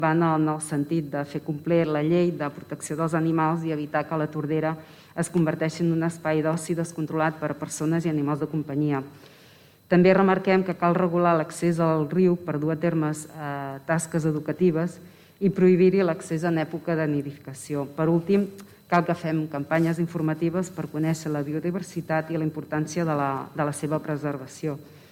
Aquest és un dels acords al qual s’ha arribat al ple de l’Ajuntament de Tordera.
La ponent de la moció i regidora d’ERC, Marta Paset, explica el sentit dels acords: